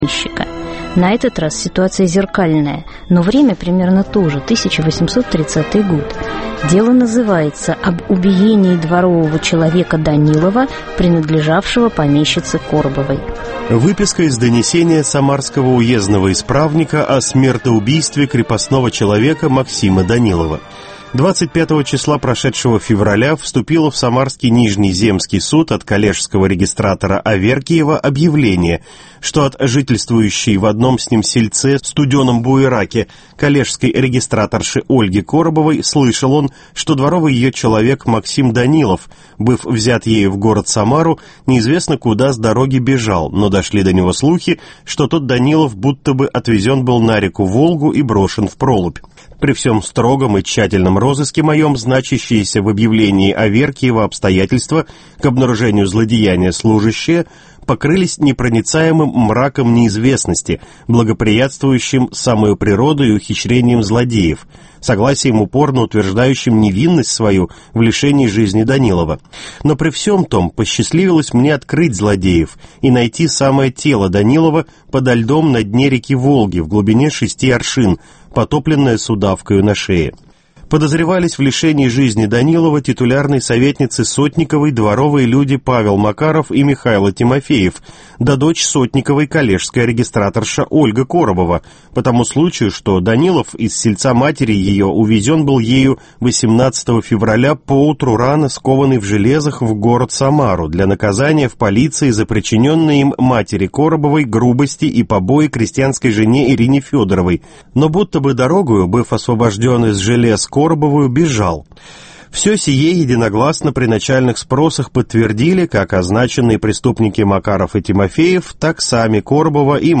В "Документах прошлого" завершающая передача цикла, посвященного проблемам взаимоотношений помещиков и крепостных, отразившихся в жандармских документах. На вопросы ведущих